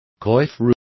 Complete with pronunciation of the translation of coiffure.